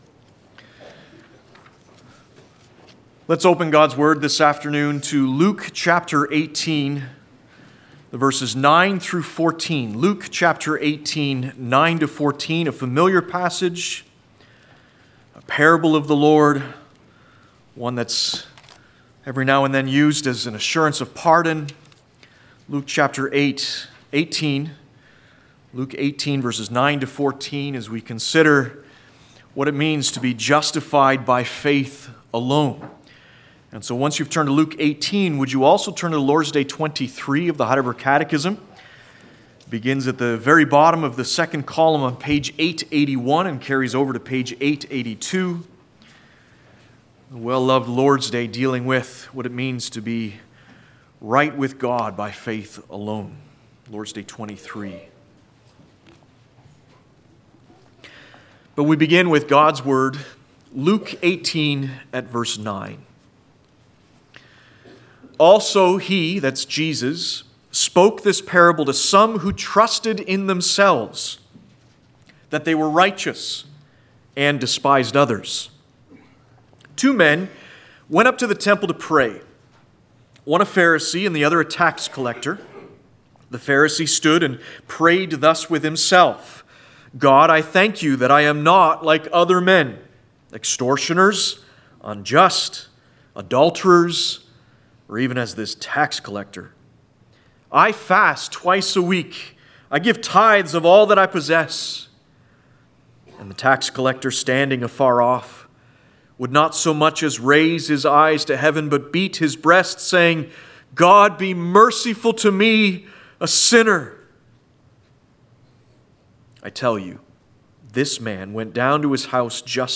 Passage: Luke 18:9-14 Service Type: Sunday Afternoon